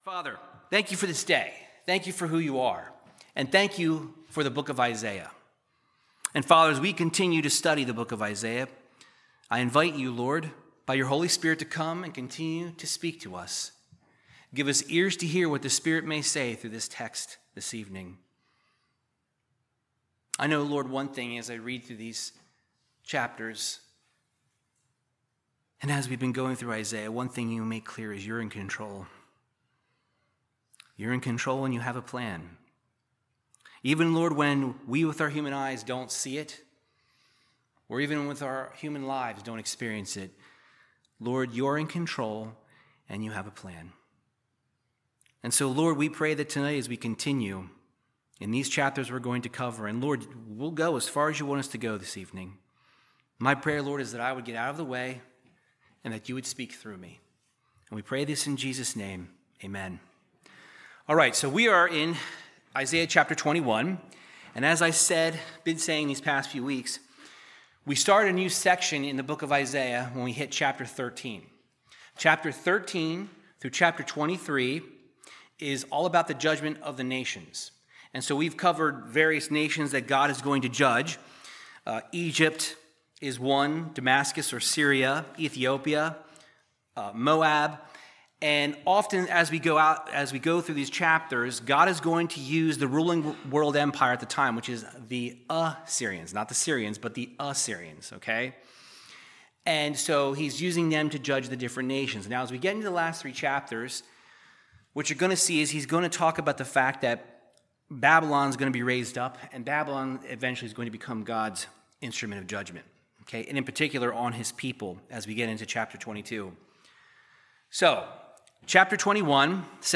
Verse by verse Bible teaching through Isaiah chapters 21 through 22